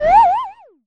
fun_wobble_effect_01.wav